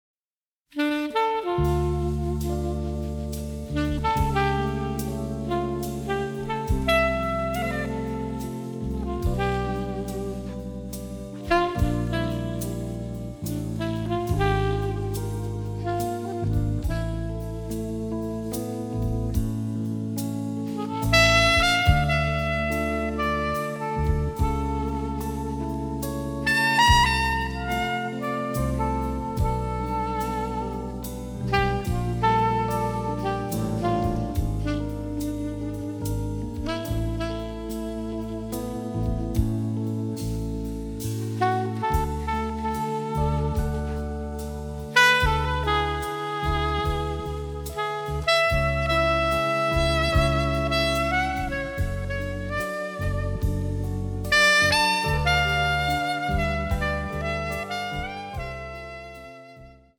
noir score
record the music in Paris